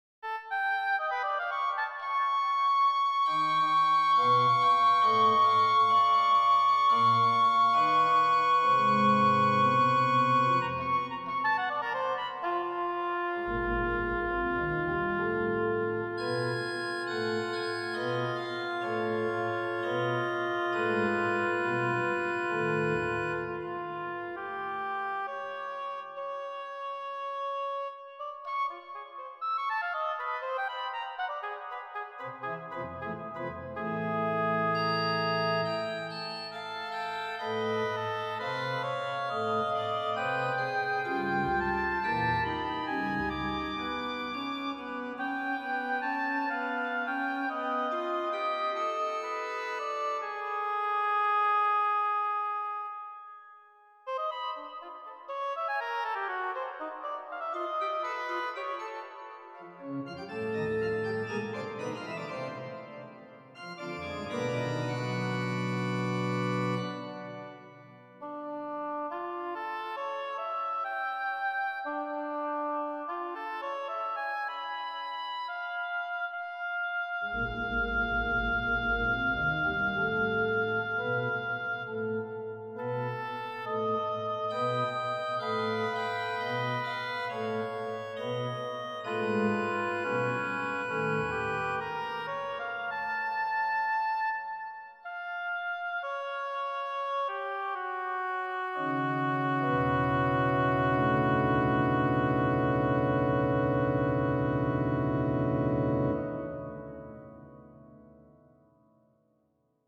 He did write 3 pieces for Organ and Oboe that are simply magical:
KropfreiterOboe-Organ3Stueke2.mp3